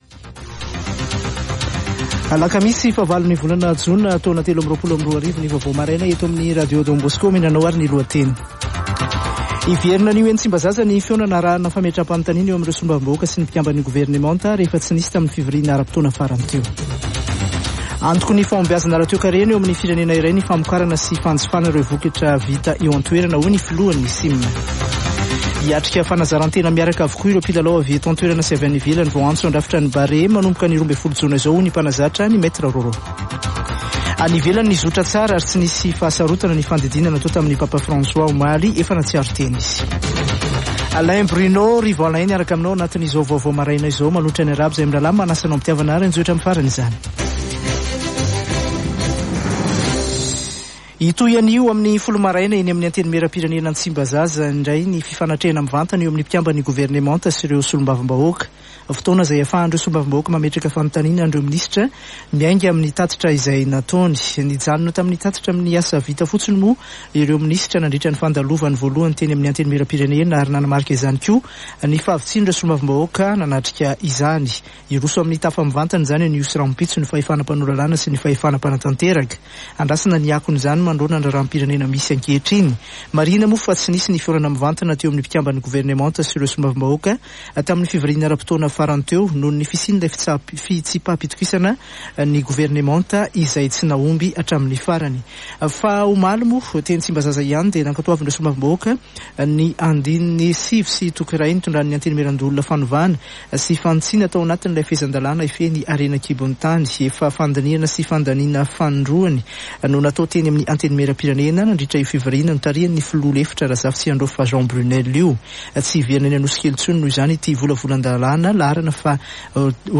[Vaovao maraina] Alakamisy 8 jona 2023